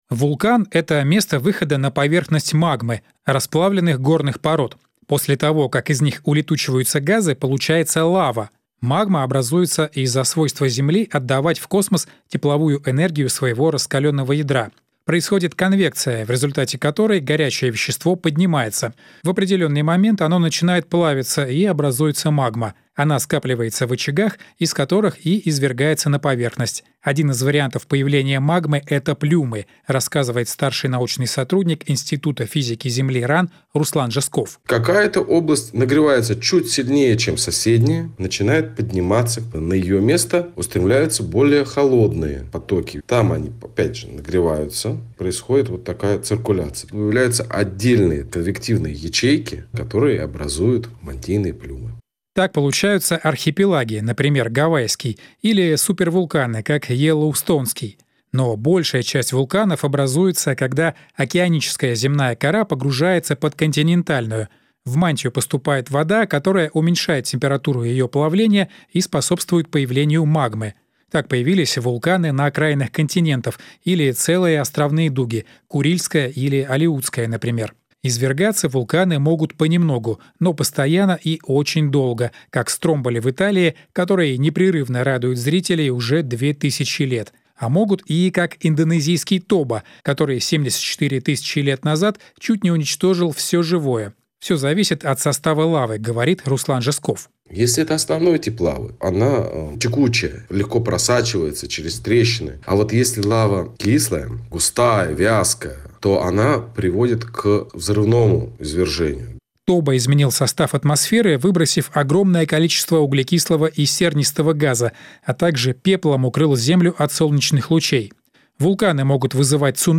Эфир на радио «Звезда».